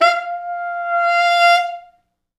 Index of /90_sSampleCDs/East West - Quantum Leap Horns Sax/Quantum Leap Horns Sax/T Sax fts